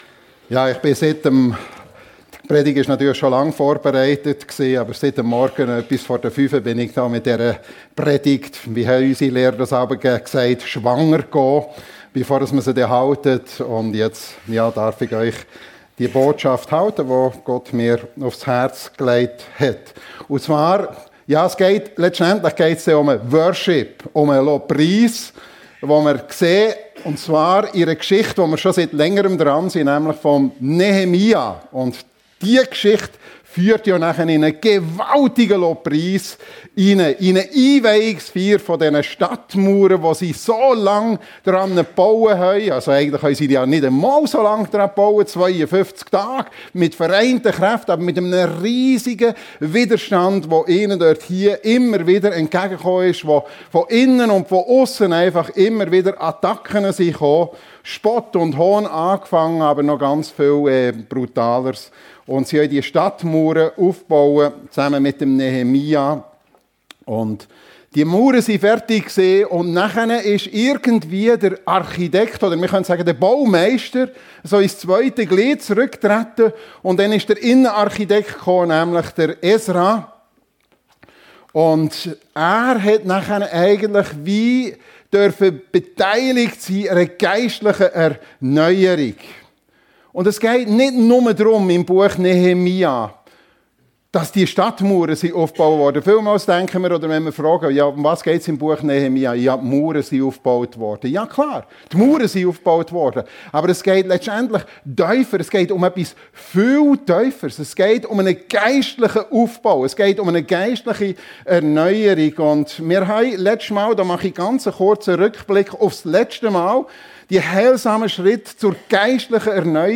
Nehemia - Vorbildhafter Worship (Teil 7) ~ FEG Sumiswald - Predigten Podcast